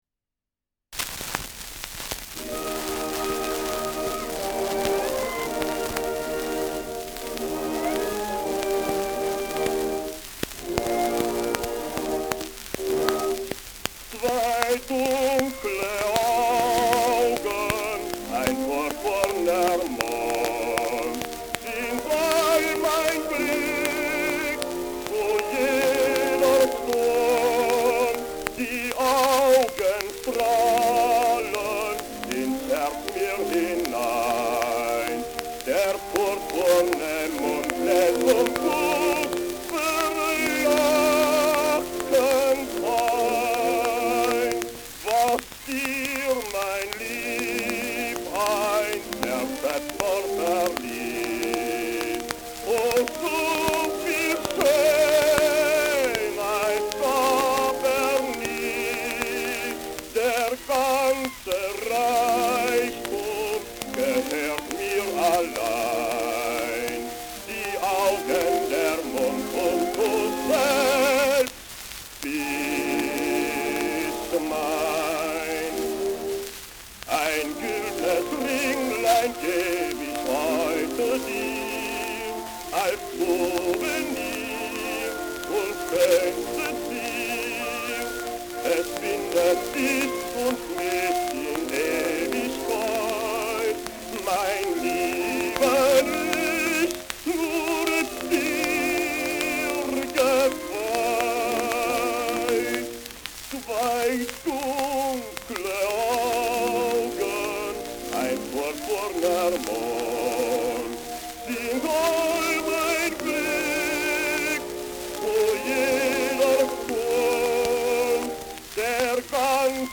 Schellackplatte
Stark abgespielt : Durchgehend starkes Knacken im ersten Drittel : Erhöhtes Grundrauschen : sonst Gelegentlich stärkeres Knacken
[unbekanntes Ensemble] (Interpretation)
German Bass w. Orch.